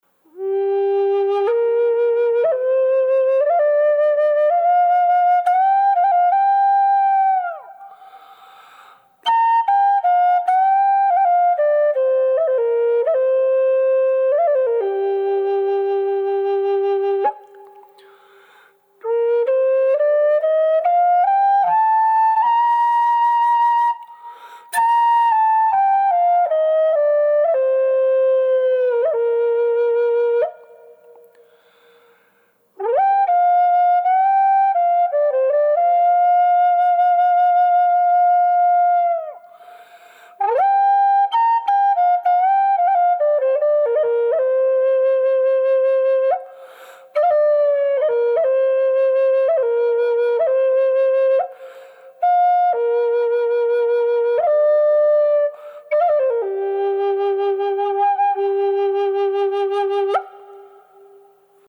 The sound is as big as any of my Gm flutes.
Tiger_G_Wet.mp3